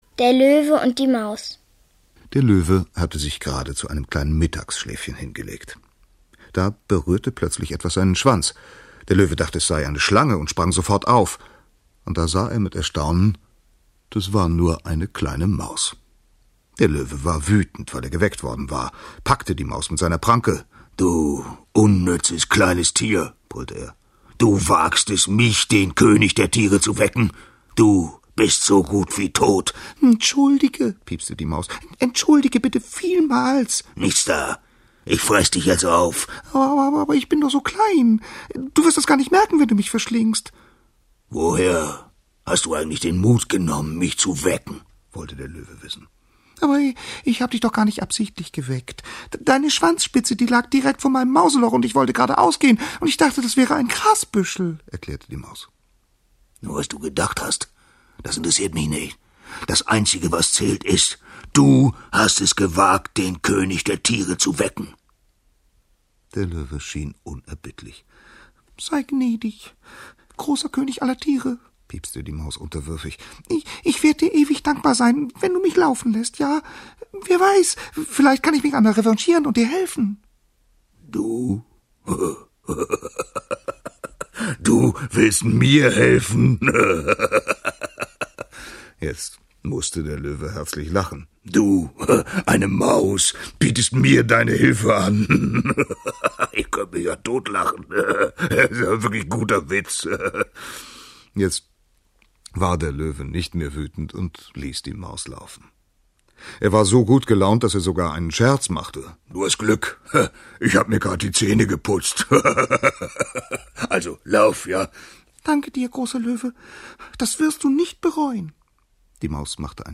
Fabel Hörverständnis